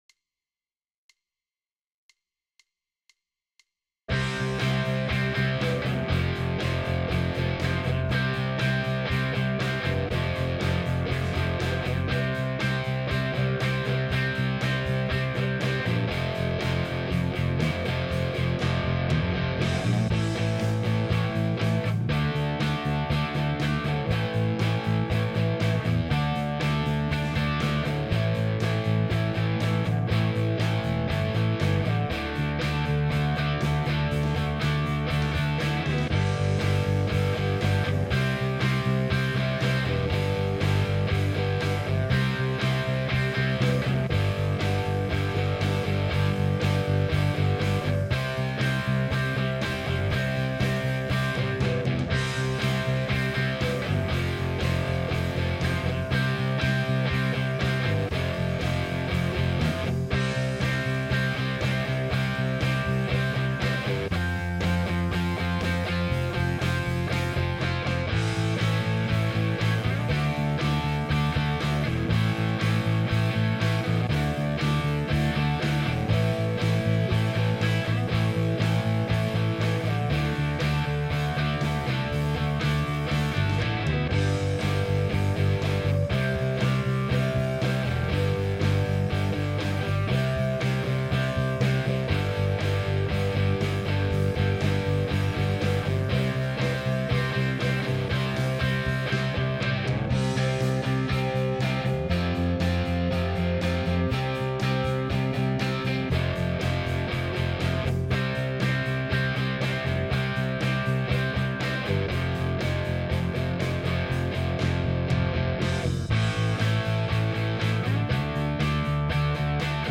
I made a test song, that just has 8 bar sections, of going from 1 to 4, in these keys C, A, G
The transitions all sound fine to me.
This uses 631 for the guitar: Real Tracks-PopAmericanDirty Ev